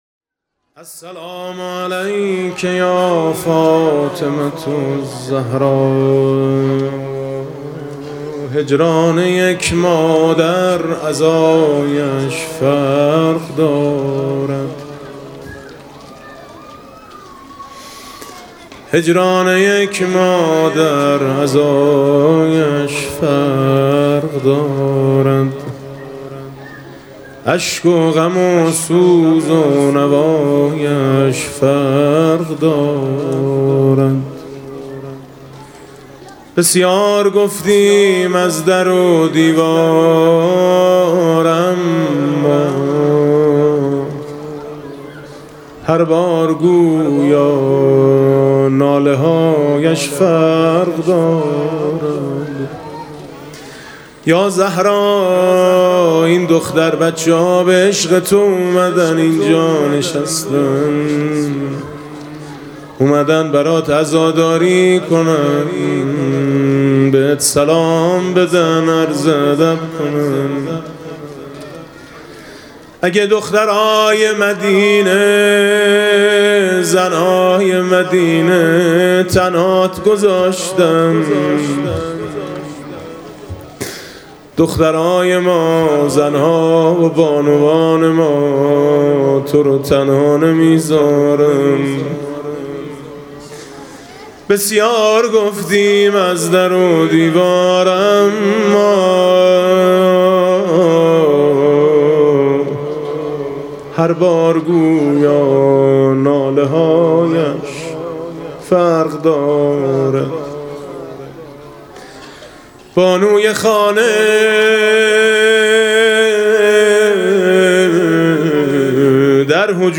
هیأت دخترانه به مناسبت عزاداری ایام شهادت حضرت زهرا (س) و استغاثه طلب باران (قرائت دعای ۱۹ صحیفه سجادیه)
با مداحی: حاج میثم مطیعی
روضه حضرت زهرا (س) برای دختر خانم‌ها